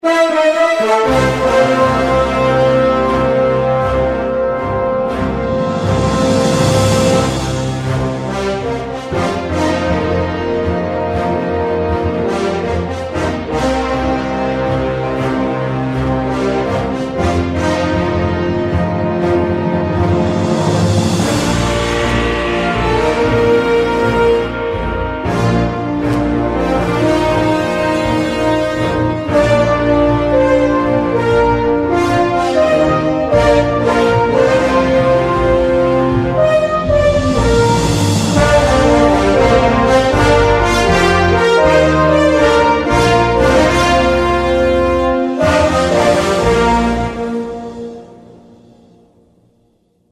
Sintonia de l'emissora